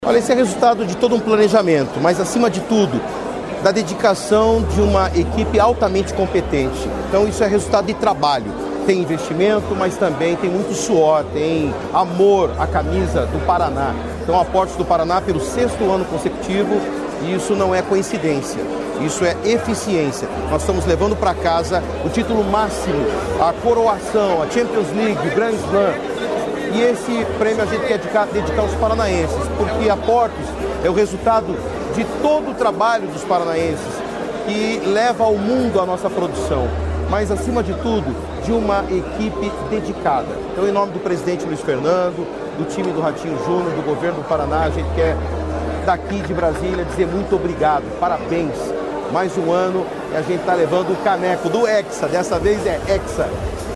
Sonora do secretário de Infraestrutura e Logística, Sandro Alex, sobre a Portos do Paraná ser hexacampeã do principal prêmio de gestão portuária do Brasil